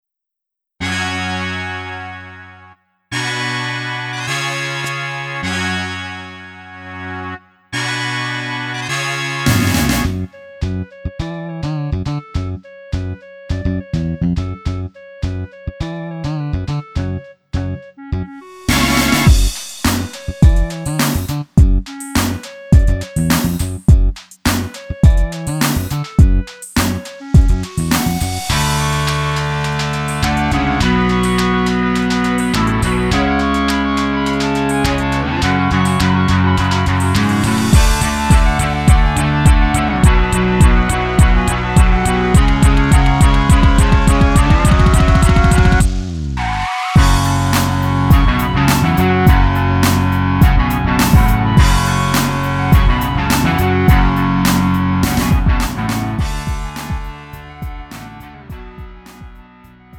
축가, 웨딩, 결혼식 MR. 원하는 MR 즉시 다운로드 가능.
음정 원키 장르 가요 구분 Lite MR